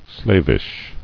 [slav·ish]